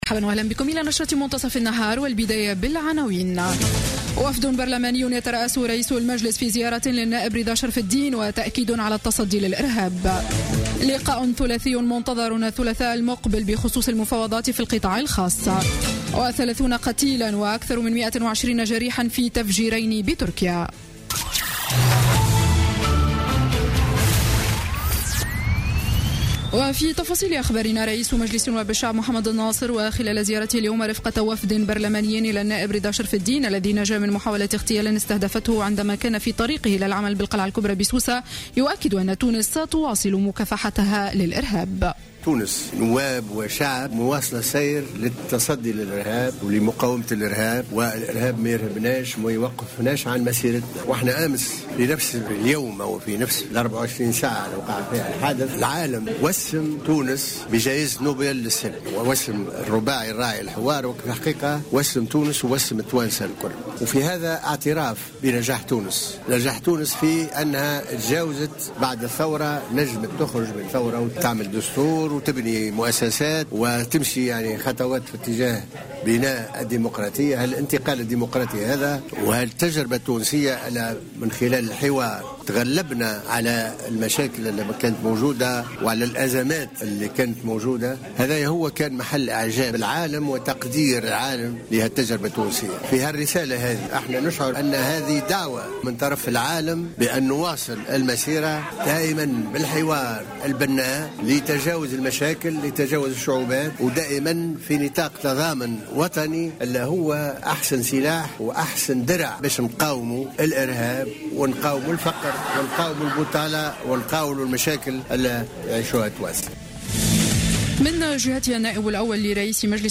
نشرة أخبار منتصف النهار ليوم السبت 10 أكتوبر 2015